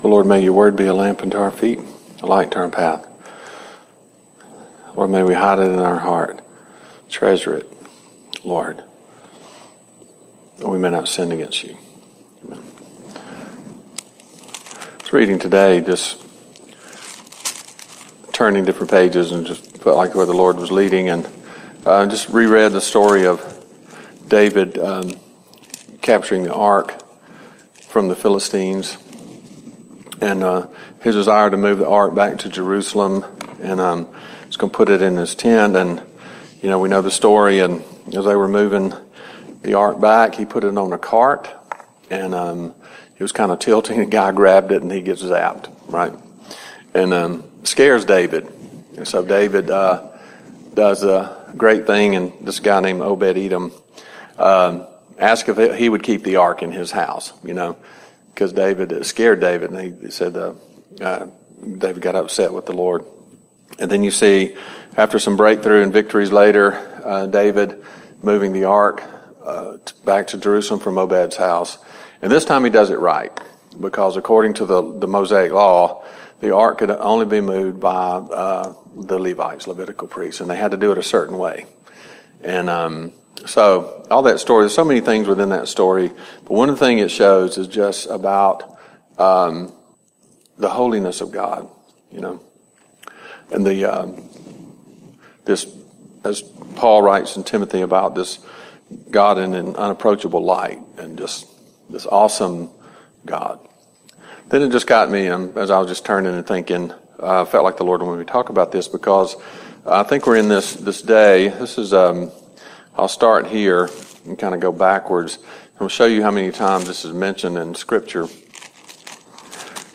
Service Type: CTK Noon Service